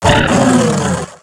Cri de Diamat dans Pokémon X et Y.